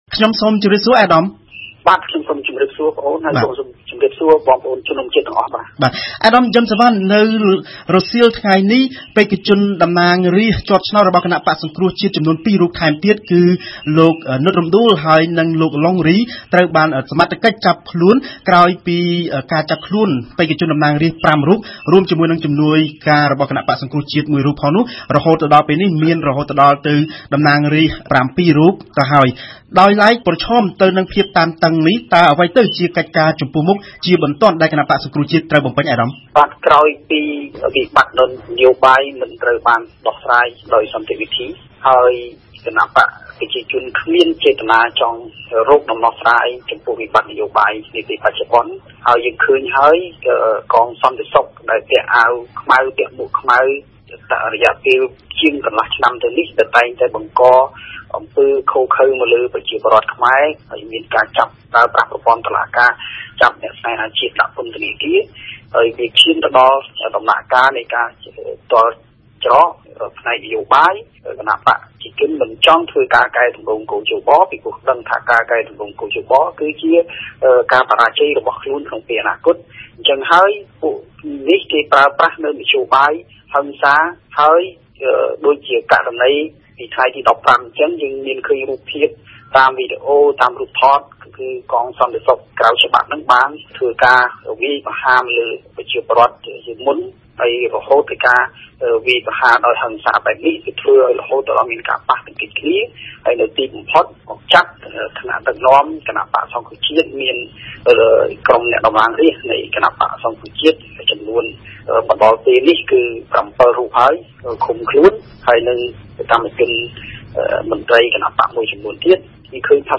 ភ្ញៀវប្រចាំថ្ងៃរបស់វិទ្យុបារាំងអន្តរជាតិ RFI នៅថ្ងៃនេះ គឺលោក យឹម សុវណ្ណ អ្នកនាំពាក្យគណបក្សសង្គ្រោះជាតិ។